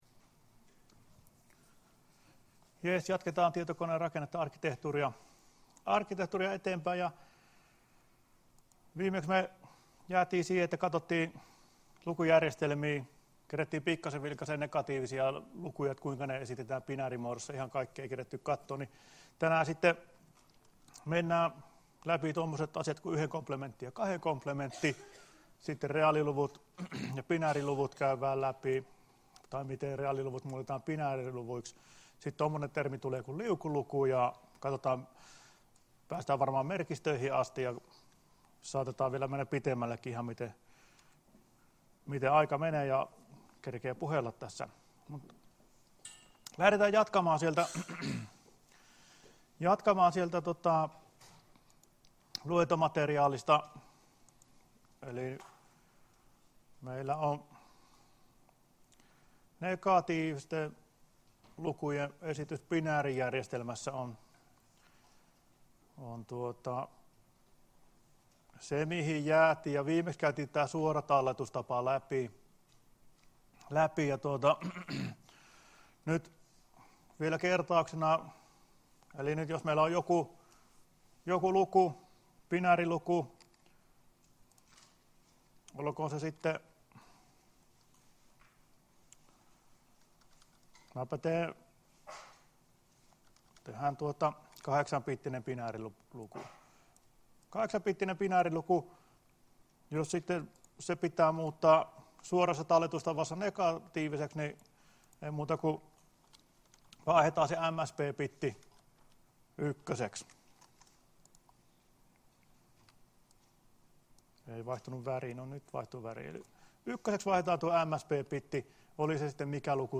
Luento 1.11.2017 — Moniviestin